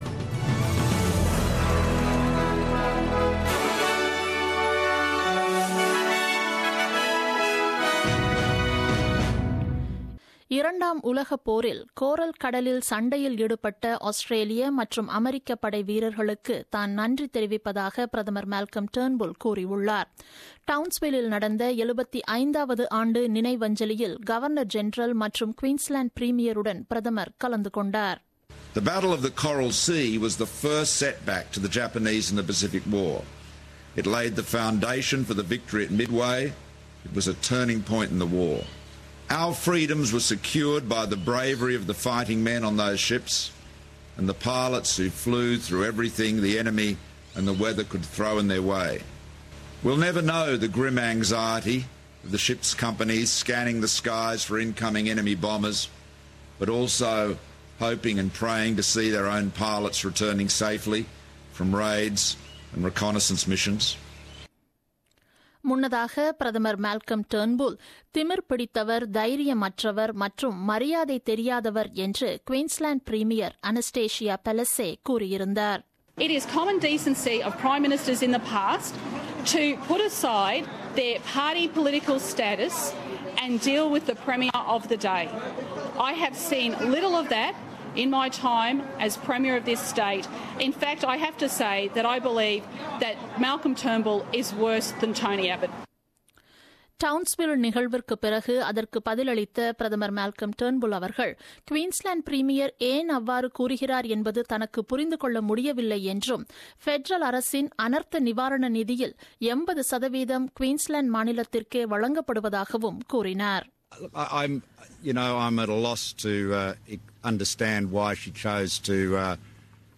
The news bulletin broadcasted on 1st May 2017 at 8pm.